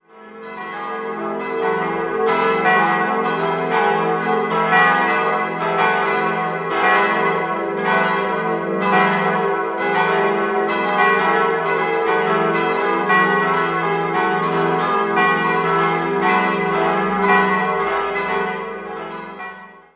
Die Glocken 1, 2 und 4 wurden 1951 von Georg Hofweber in Regensburg gegossen, bei der gis' dürfte es sich um eine alte Glocke des Vorgängergeläuts von Karl Hamm aus dem Jahr 1909 handeln. Alle Glocken erhielten im Jahr 2007 neue Klöppel.